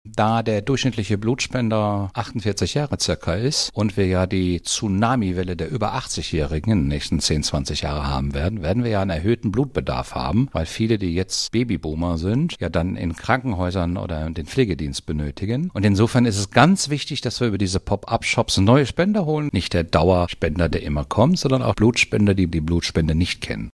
Radio Siegen-Interview